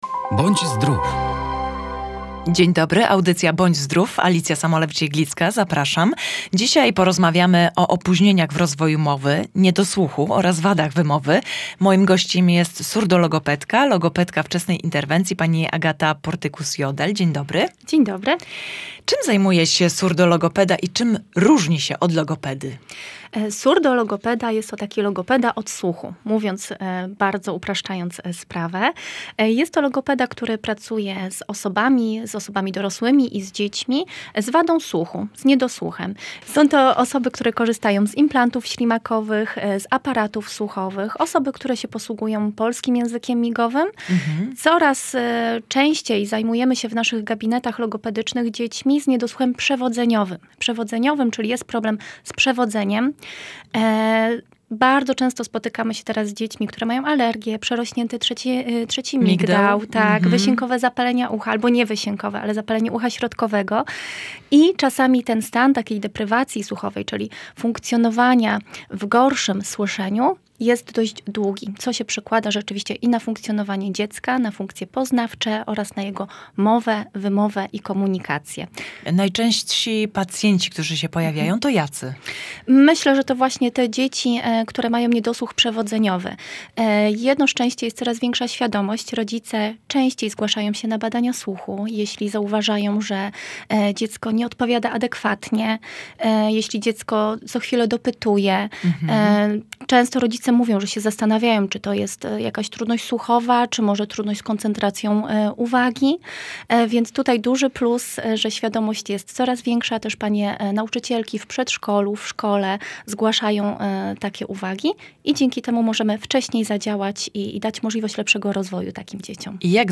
Odpowiada surdologopeda